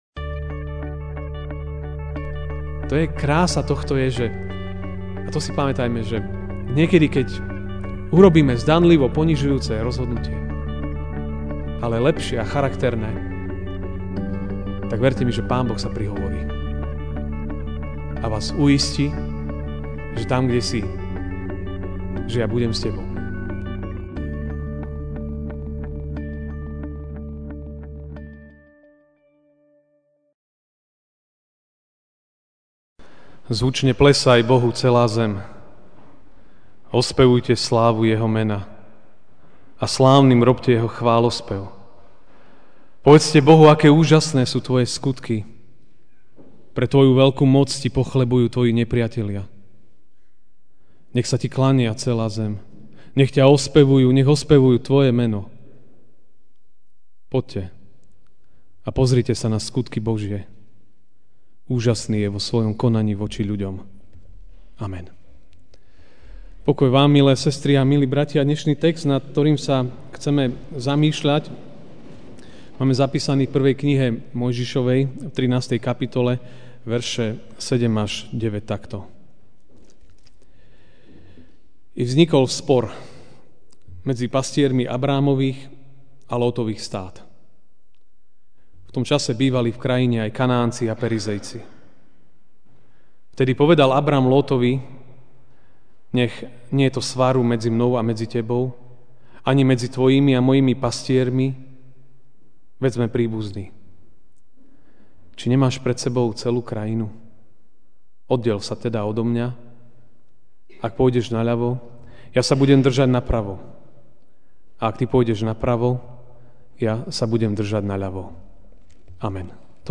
Ranná kázeň: Ako môžeme rozpoznať ľudí viery (1M 13, 7-9) I vznikol spor medzi pastiermi Abrámových a Lótových stád.